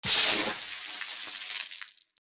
水ロケットの水を浴びる C1-01
/ G｜音を出すもの / Ｇ-15 おもちゃ